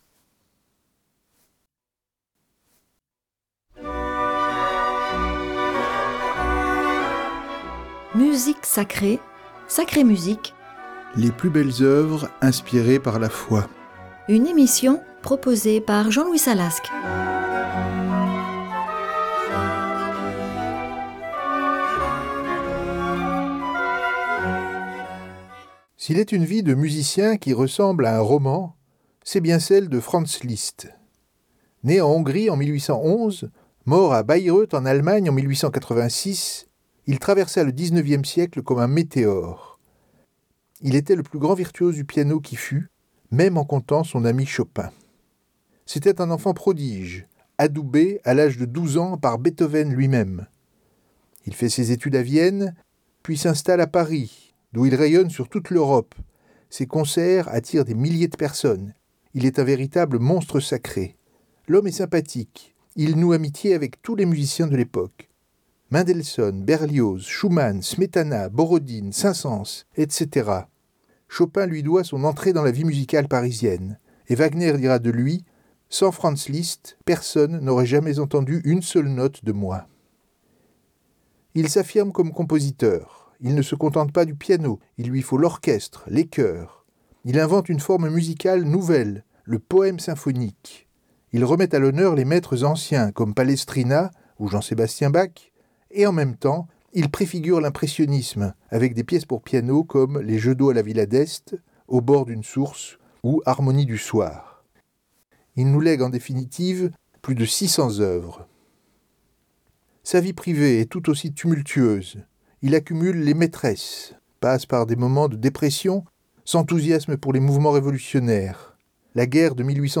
Liszt met sa virtuosité pianistique au service de l'évocation de Saint François d'Assise et de Saint François de Paule.